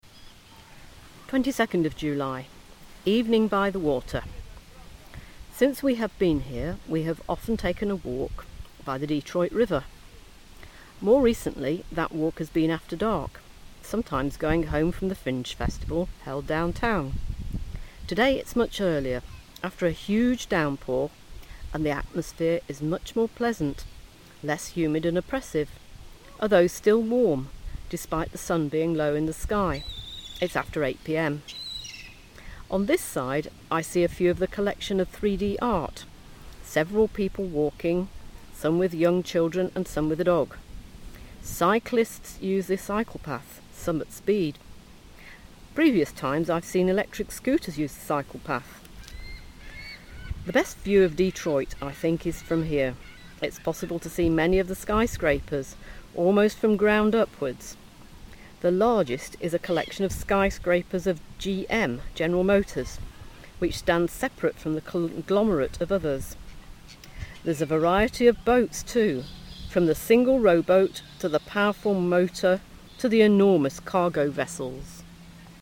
Evening by the water (outside broadcast)